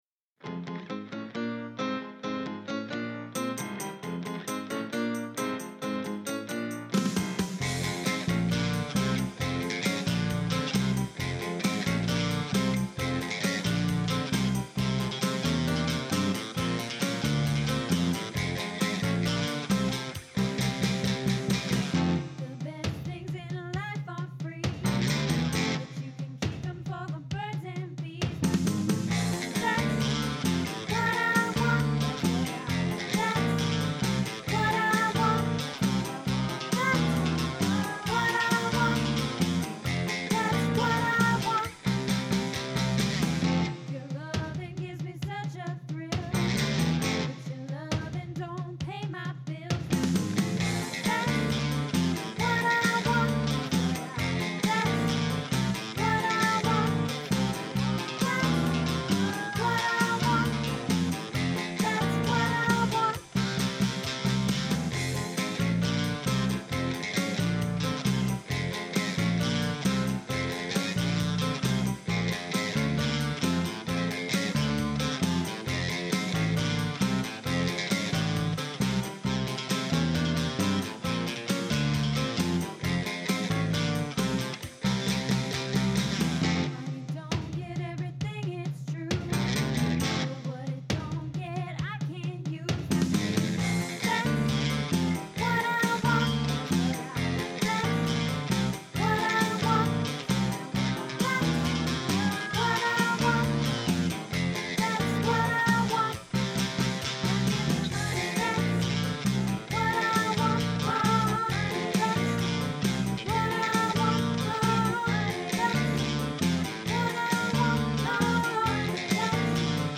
Money (That's What I Want) Soprano